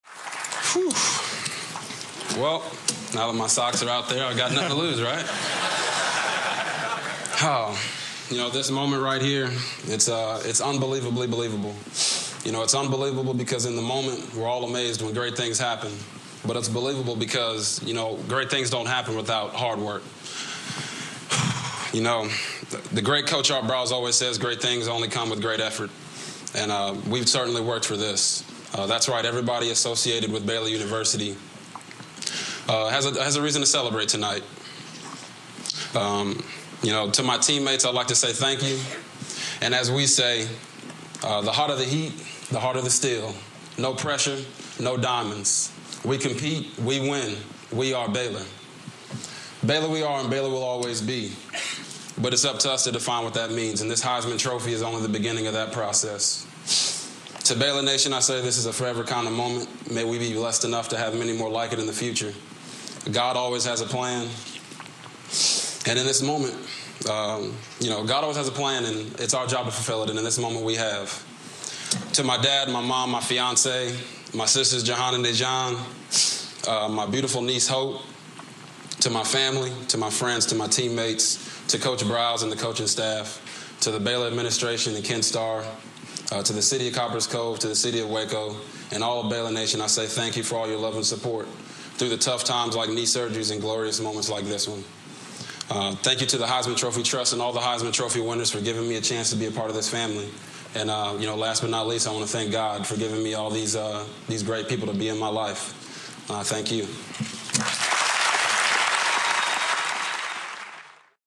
Heisman Trophy Award Acceptance Address
delivered day 10 December 2011, Times Square, New York
Audio Note: AR-XE = American Rhetoric Extreme Enhancement
robertgriffinheismantrophyacceptanceARXE.mp3